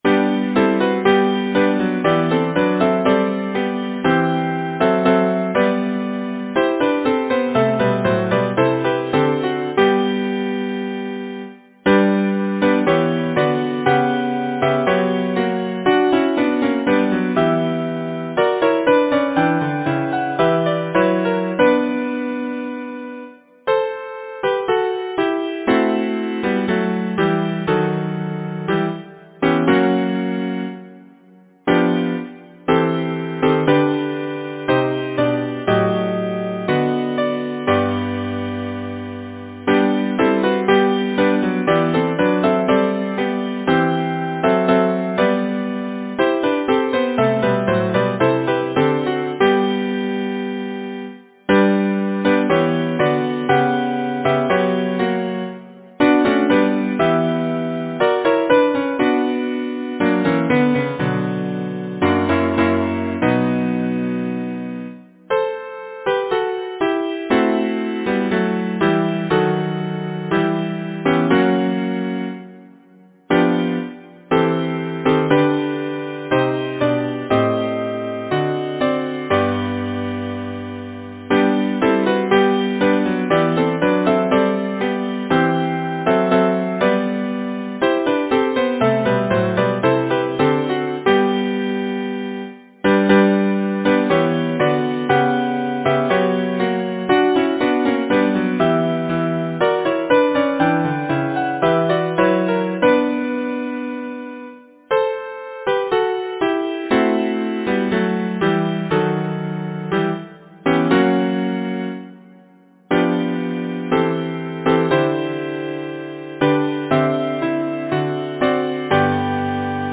Title: Love and Laughter Composer: Cyril Bradley Rootham Lyricist: Arthur Gray Butler Number of voices: 4vv Voicing: SATB Genre: Secular, Partsong
Language: English Instruments: A cappella